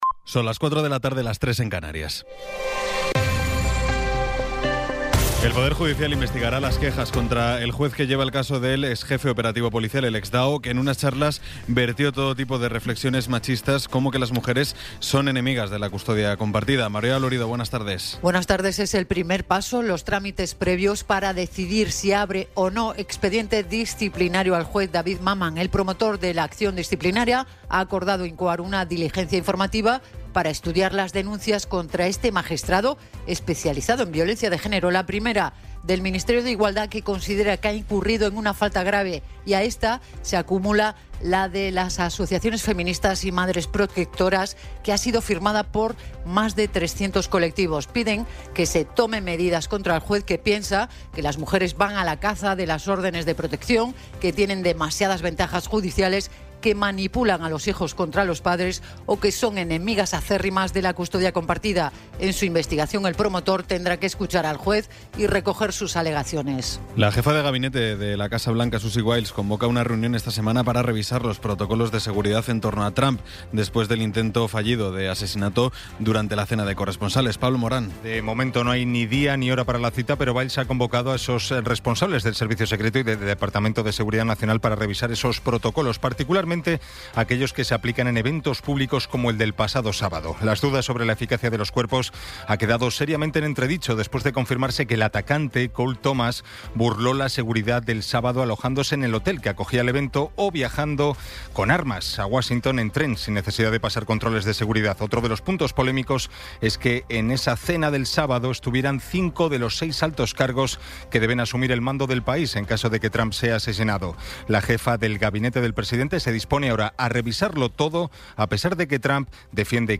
Resumen informativo con las noticias más destacadas del 27 de abril de 2026 a las cuatro de la tarde.